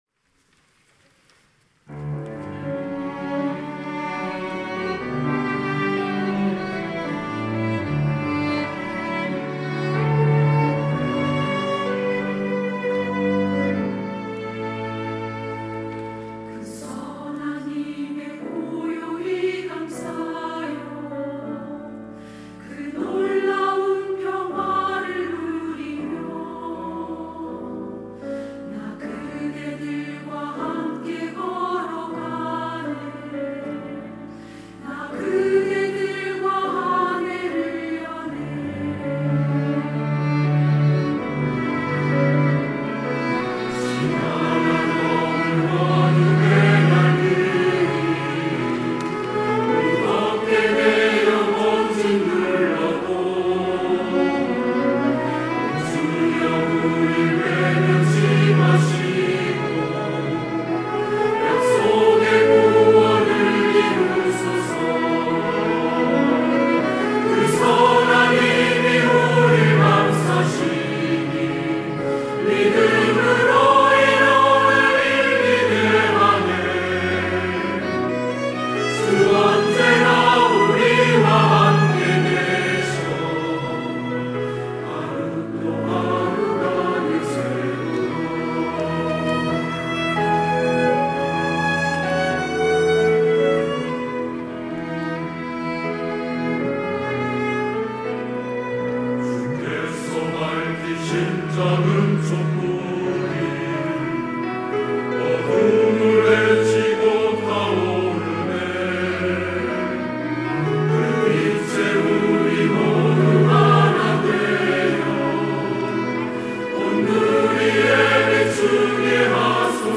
할렐루야(주일2부) - 선한 능력으로
찬양대